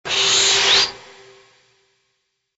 SA_shred.ogg